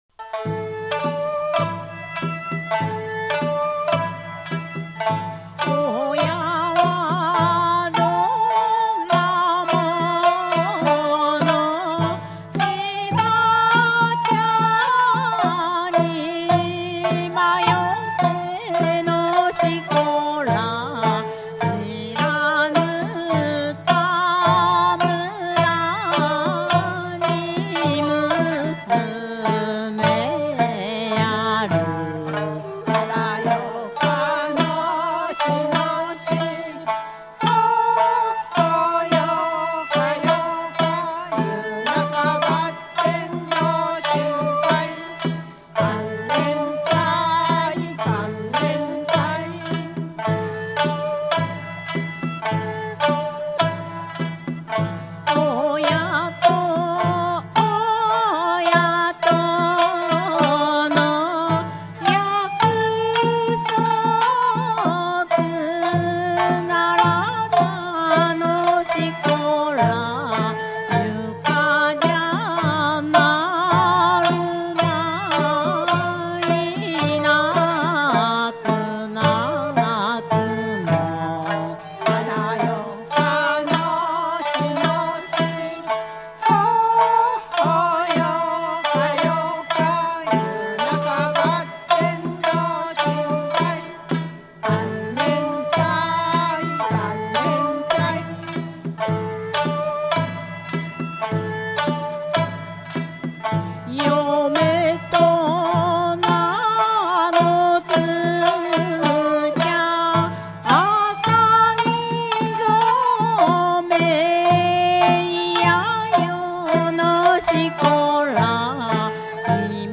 長洲嫁入り唄保存会